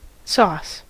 Ääntäminen
Vaihtoehtoiset kirjoitusmuodot (rikkinäinen englanti) sarse (vanhentunut) sawce (nettislangi) source Ääntäminen US : IPA : [sɒːs] US : IPA : [sɔs] IPA : [sɑs] RP : IPA : [sɔːs] Haettu sana löytyi näillä lähdekielillä: englanti Käännös Ääninäyte Substantiivit 1.